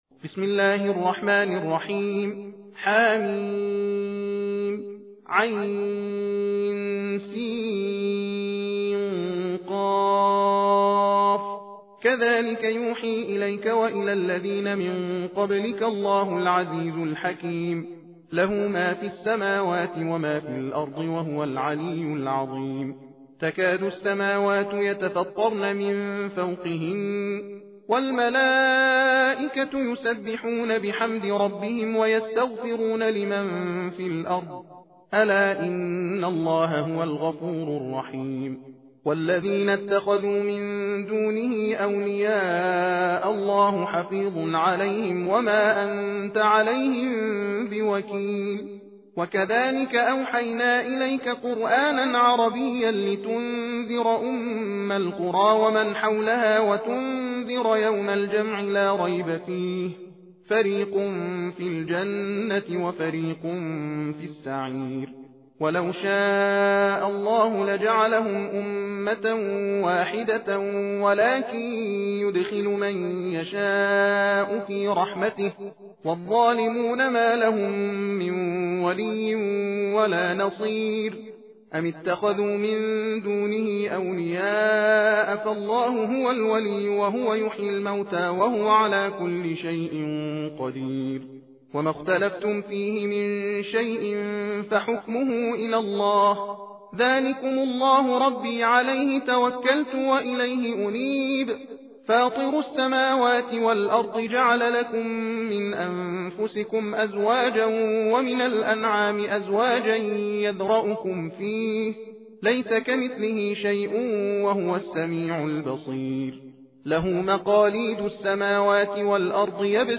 تحدیر (تندخوانی) سوره شوری
تحدیر روشی از تلاوت قرآن است که قاری در آن علی رغم رعایت کردن قواعد تجوید، از سرعت در خواندن نیز بهره می برد، از این رو در زمان یکسان نسبت به ترتیل و تحقیق تعداد آیات بیشتری تلاوت می شود.به دلیل سرعت بالا در تلاوت از این روش برای مجالس ختم قرآن کریم نیز می توان بهره برد.